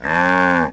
moo1.wav